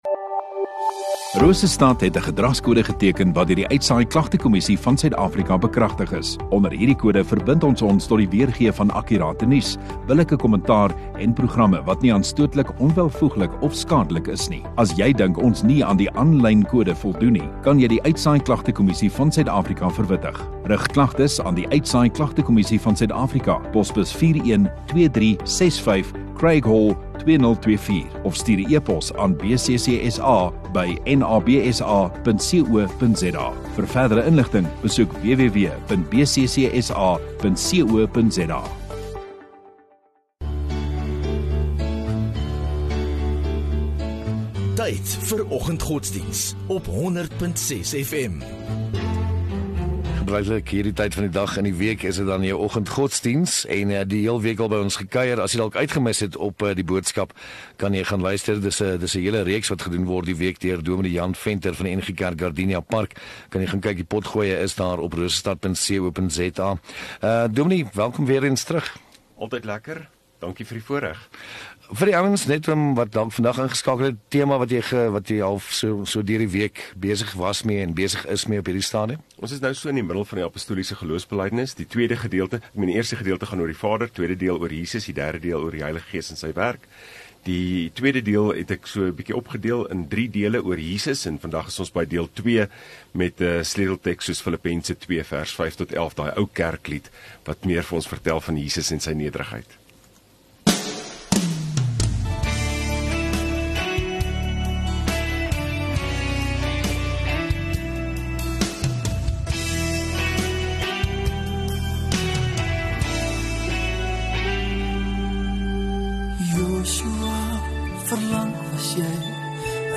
2 Nov Donderdag Oggenddiens